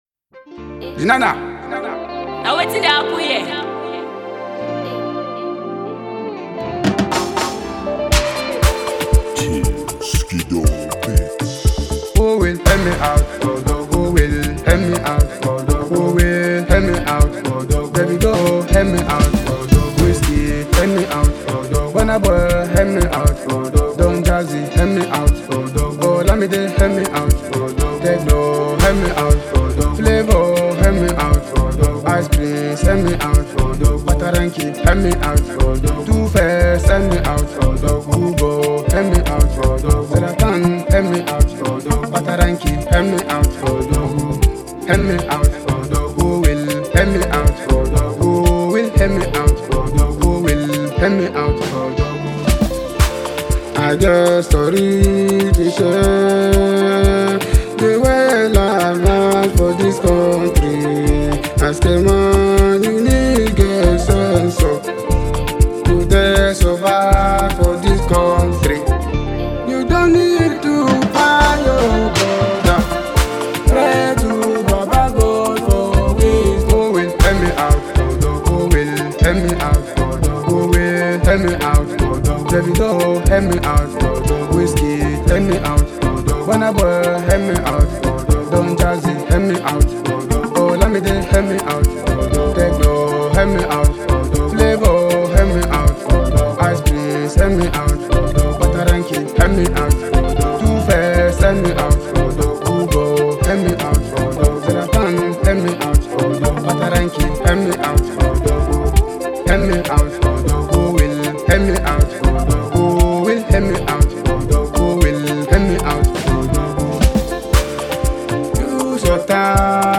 Plateau State-born Afrobeats singer